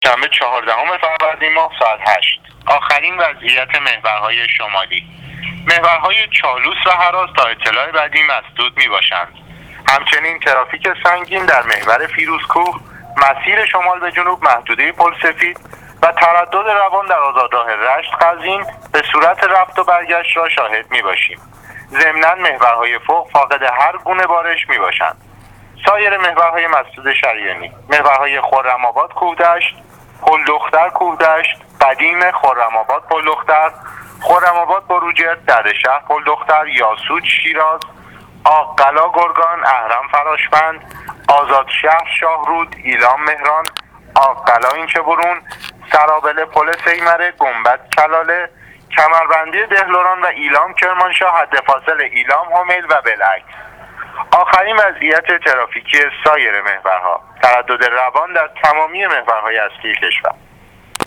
گزارش رادیو اینترنتی وزارت راه و شهرسازی از آخرین وضعیت‌ ترافیکی راه‌ها تا ساعت ۸ صبح چهاردهم فروردین/محور چالوس و هراز مسدود است/ترافیک سنگین در فیروزکوه/ترافیک روان در آزادراه رشت-قزوین